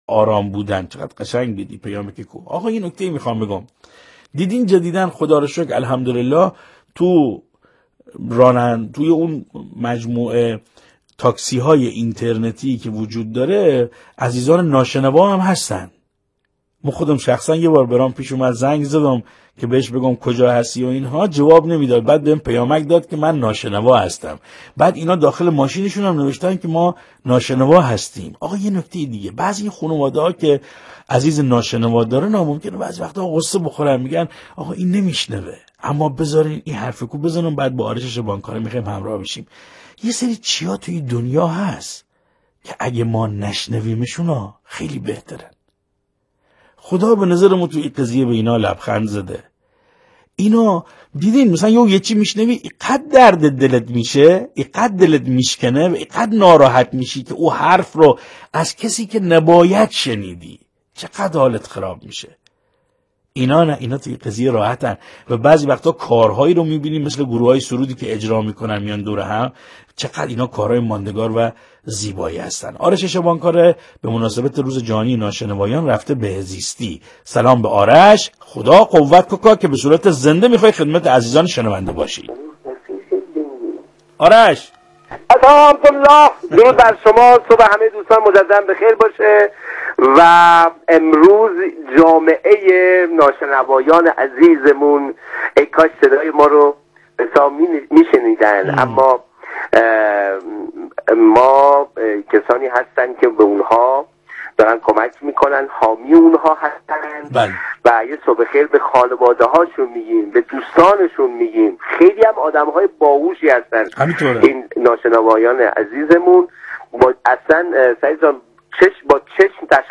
در گفتگو با برنامه زنده “صبحگاهی رادیو سلام صدا و سیمای مرکز بوشهر”